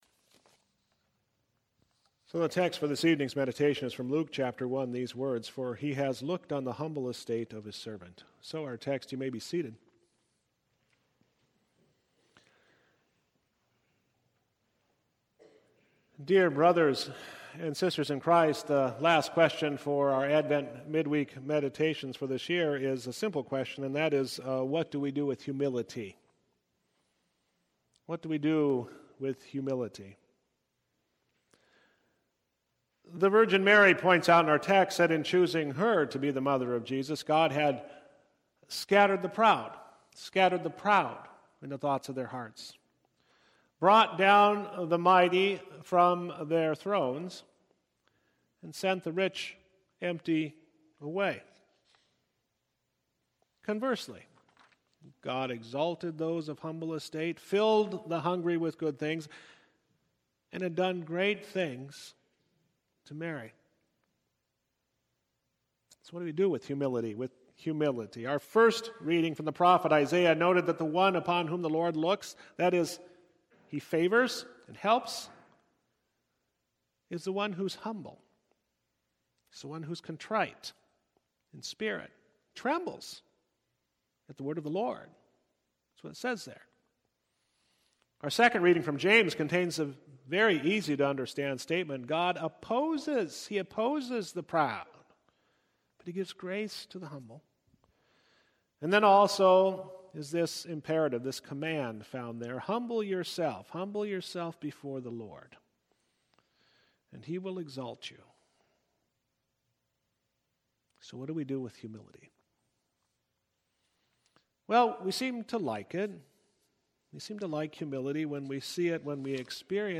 Series: Advent Midweek